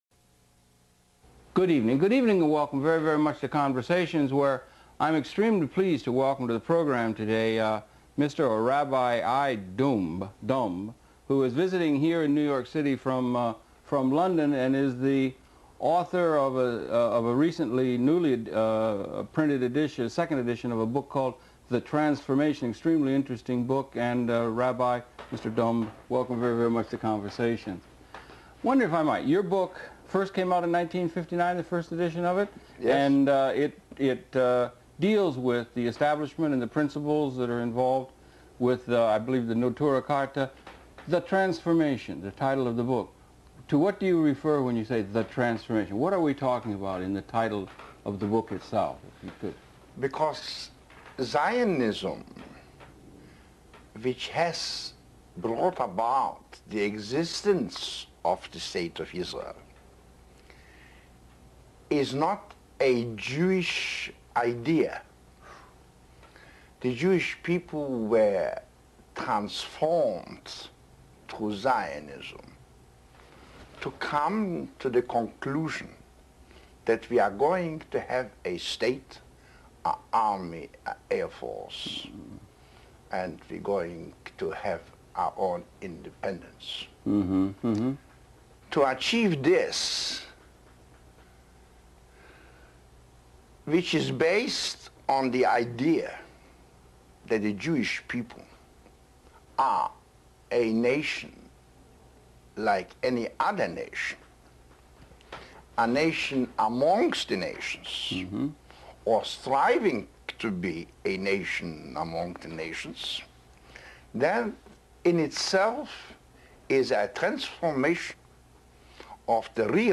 ראיון